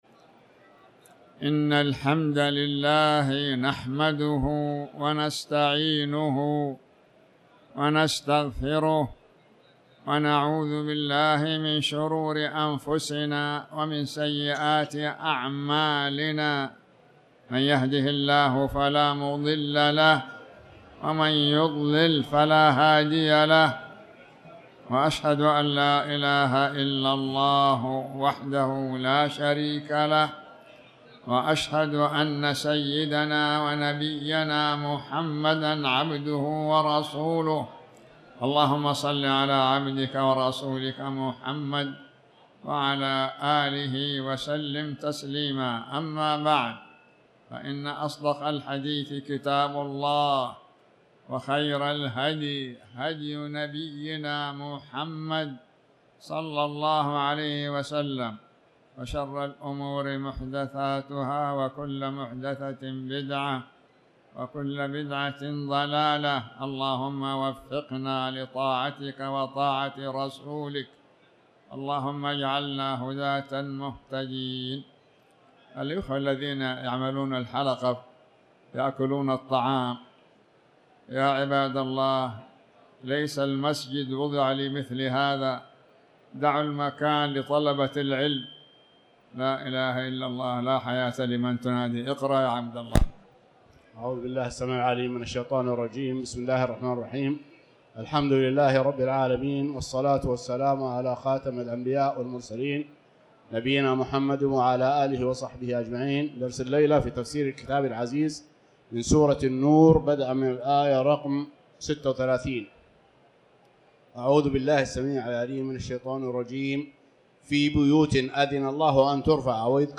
تاريخ النشر ١ جمادى الأولى ١٤٤٠ هـ المكان: المسجد الحرام الشيخ